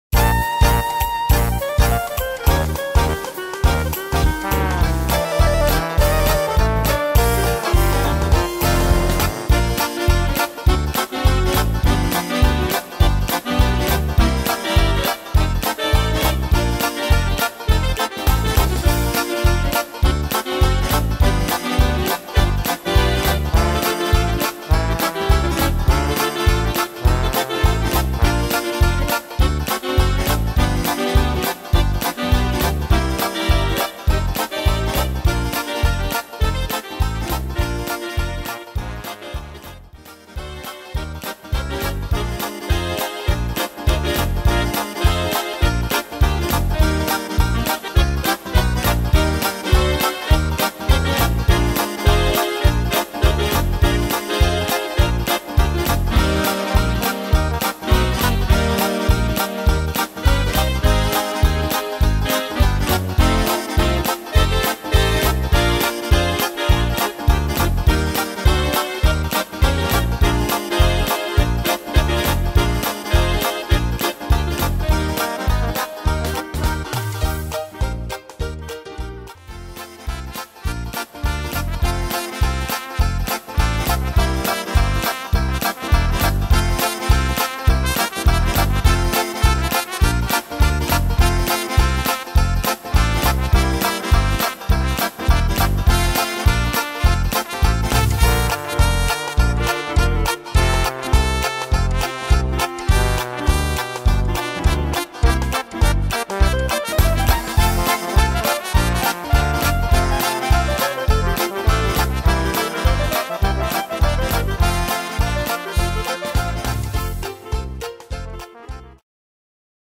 Tempo: 205 / Tonart: Bb-Dur